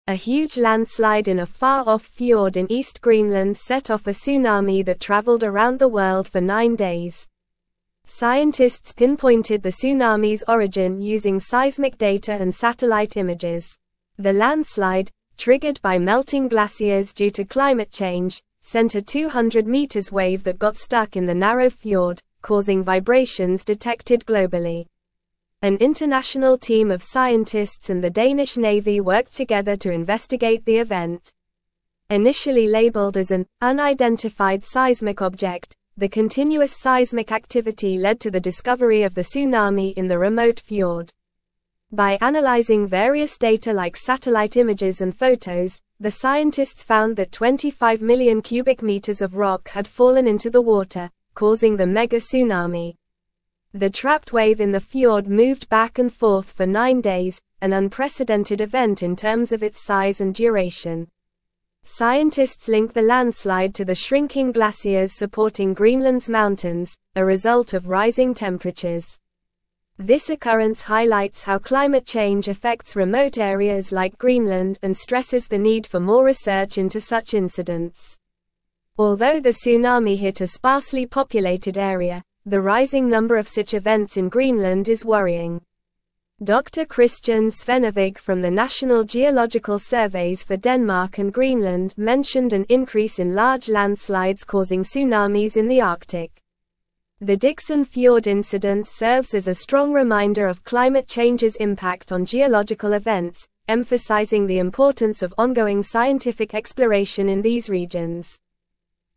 Normal
English-Newsroom-Upper-Intermediate-NORMAL-Reading-Greenland-Tsunami-Scientists-Unravel-Cause-of-Mysterious-Tremors.mp3